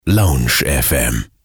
Wie man LoungeFM korrekt ausspricht
Aussprache-Lounge-FM.mp3